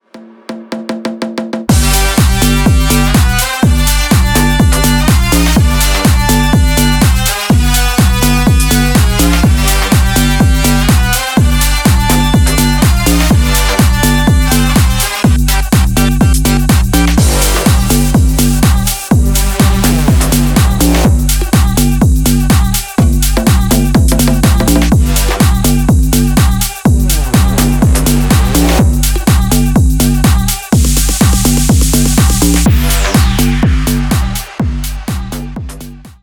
Поп Музыка # Танцевальные
клубные # громкие # без слов